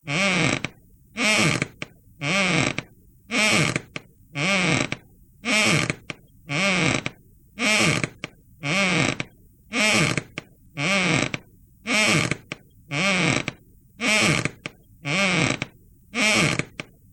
Скрип раскачивающегося кресла